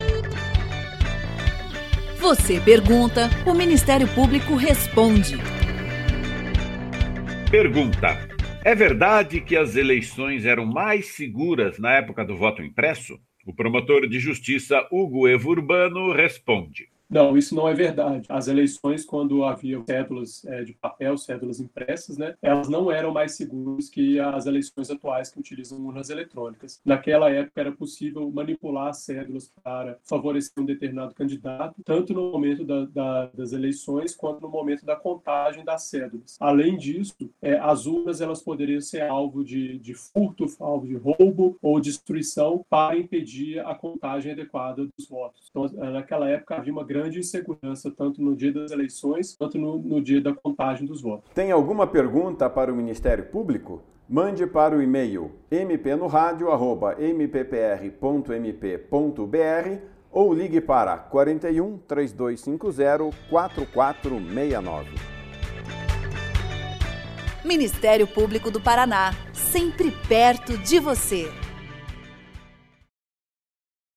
Em áudios curtos, de até um minuto, procuradores e promotores de Justiça esclarecem dúvidas da população sobre questões relacionadas às áreas de atuação do Ministério Público.
Ouça abaixo a resposta do promotor de Justiça Hugo Evo Magro Corrêa Urbano